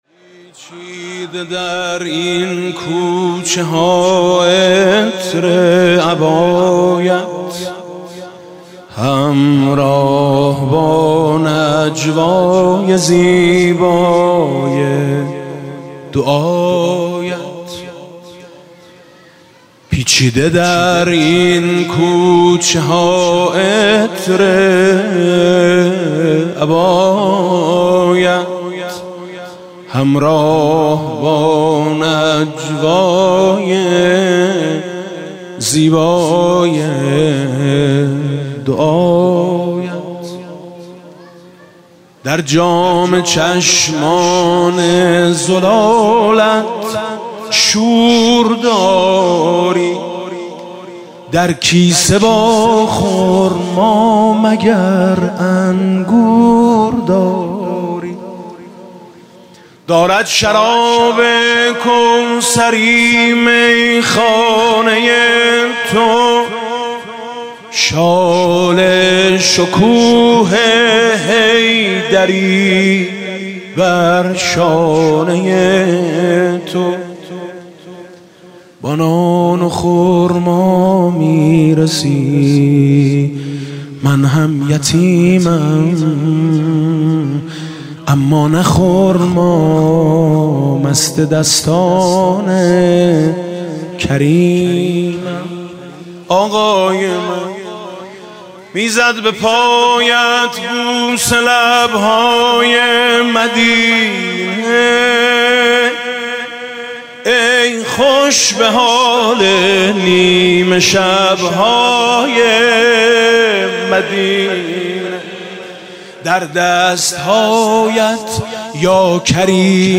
«شب پانزدهم رمضان 1397» مدح: سرمست آقای جوانان بهشتم